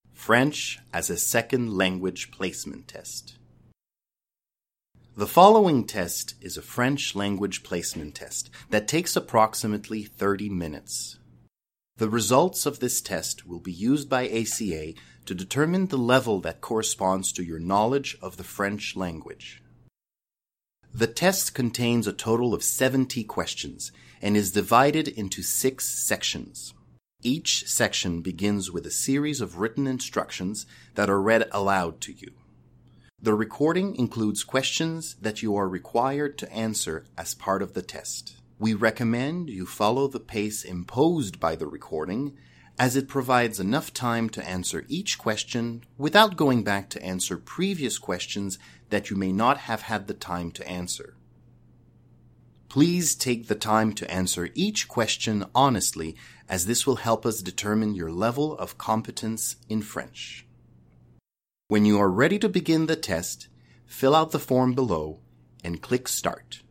Each section begins with a series of written instructions that are read aloud to you.
Part_0_Title_and_Instructions.mp3